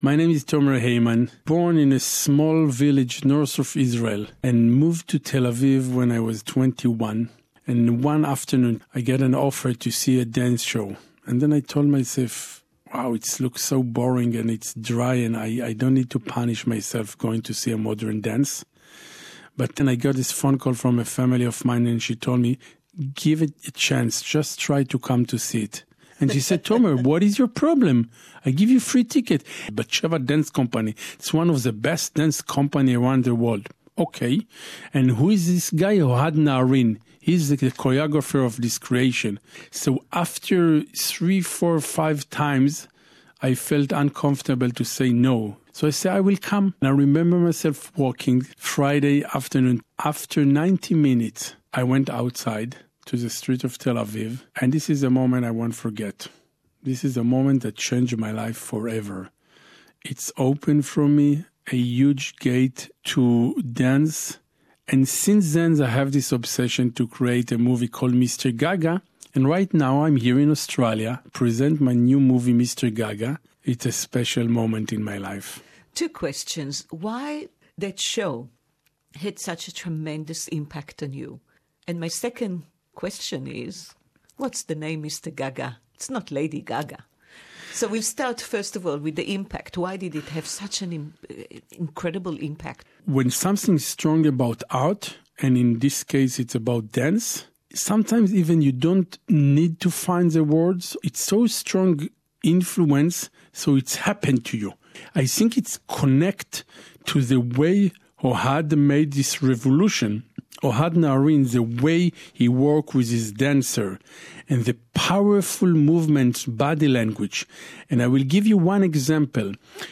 (English Interview)